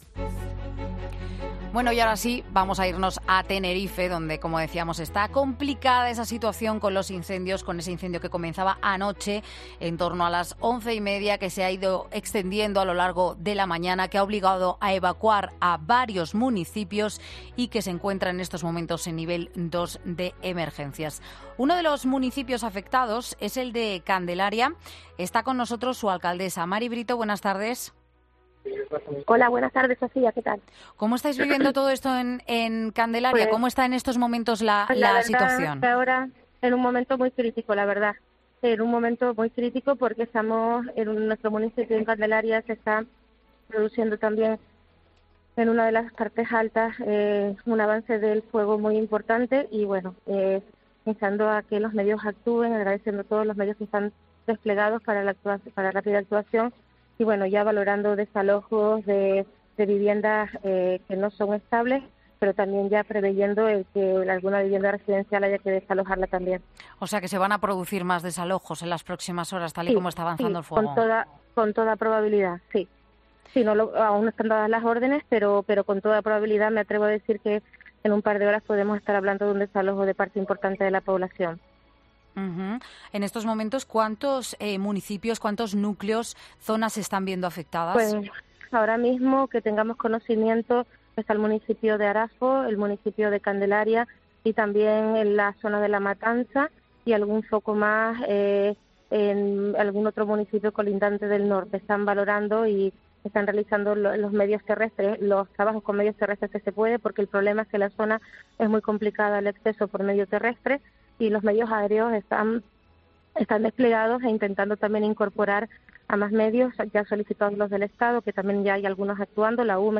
La alcaldesa de Candelaria valora el avance del incendio en Mediodía COPE: "Estamos en un momento crítico"
Así lo ha asegurado la alcaldesa de Candelaria, Mari Brito, en 'Mediodía COPE'.